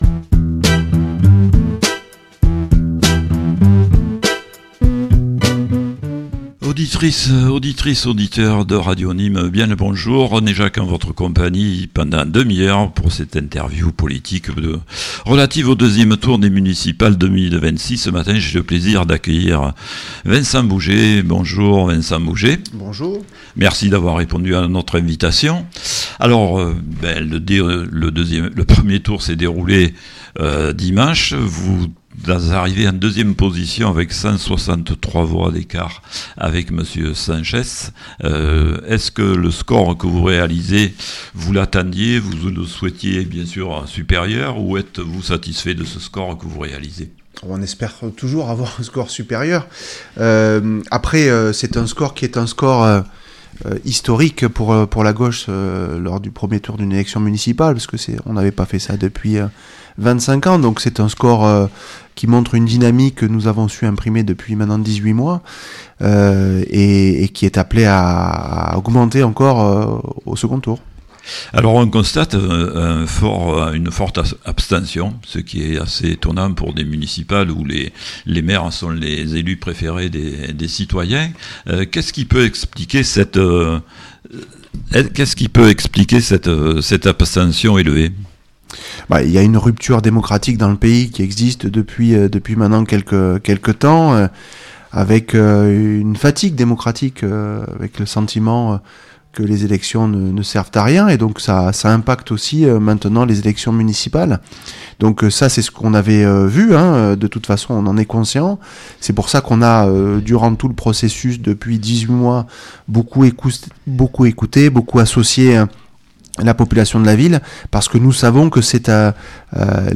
Municipales 2026 - Entretien d'entre-deux-tours